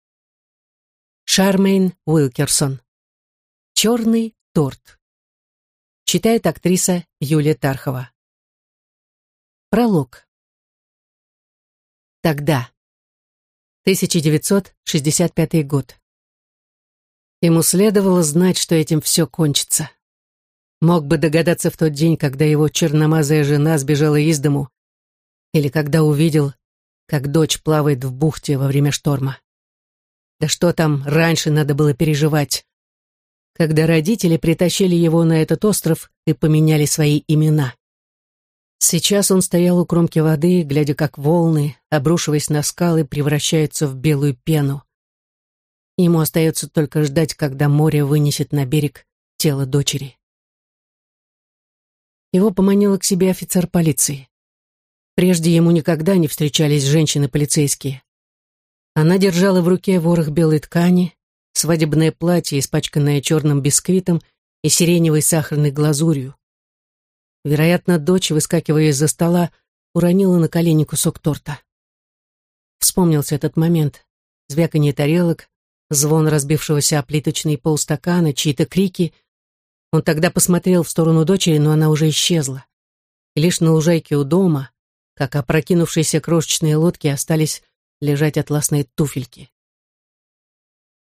Аудиокнига Черный торт | Библиотека аудиокниг